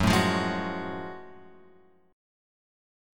F#mM9 chord {2 4 3 2 2 4} chord